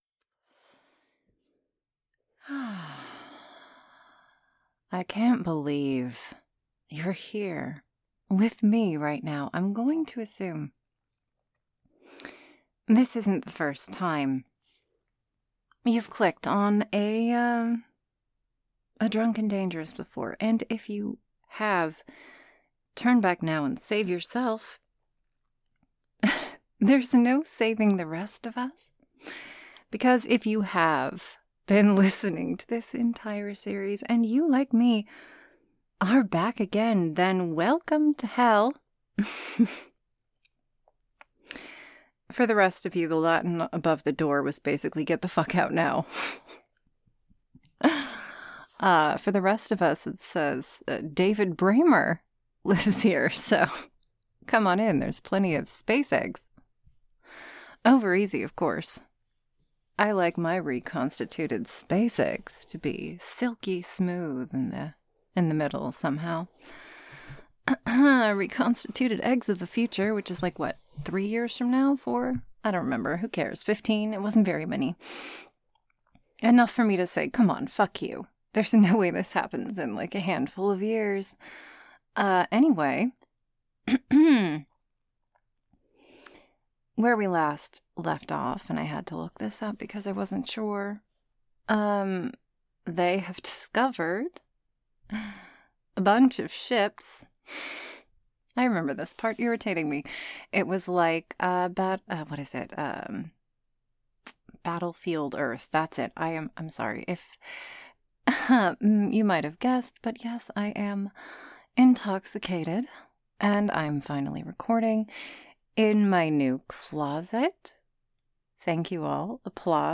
Join me once again while I continue my intoxicated rambling narration of the epic(ally frustrating) Moon People 2, written by Grief Givington*.